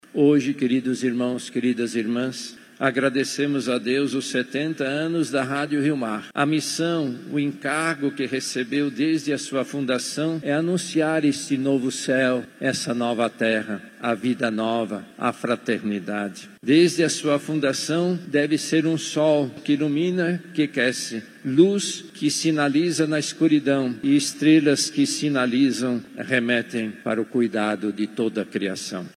Aniversários da Rádio Rio Mar e Fundação Rio Mar são celebrados com missa especial na catedral
Durante a homilia, o Cardeal Leonardo Steiner expressou gratidão pelas duas instituições e destacou o serviço essencial desenvolvido pelos colaboradores e por quem ajuda a manter esta causa.
SONORA-1-CARDEAL-STEINER.mp3